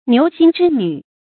牛星織女 注音： ㄋㄧㄨˊ ㄒㄧㄥ ㄓㄧ ㄋㄩˇ 讀音讀法： 意思解釋： 即牛郎織女。